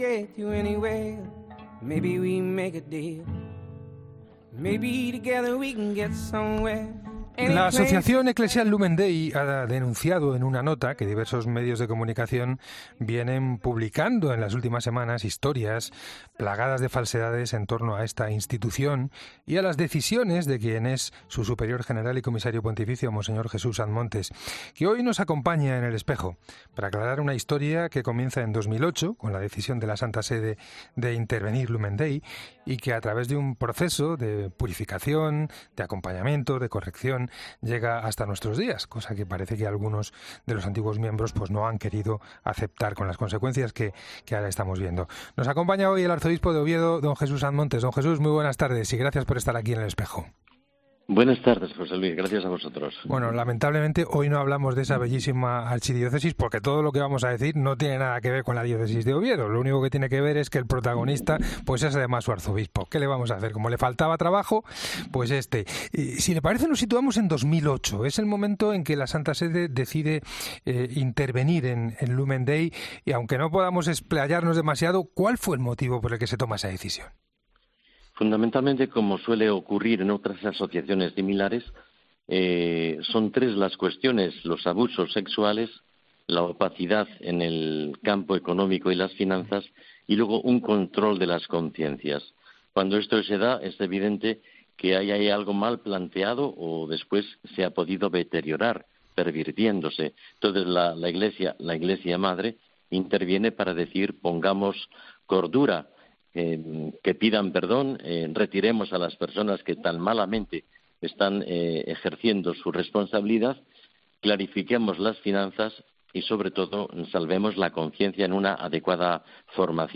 Él es una de las personas en el punto de mira de estas calumnias y acude a El Espejo de la Cadena COPE para aclarar una historia que comienza en 2008 con la intervención de la Santa Sede.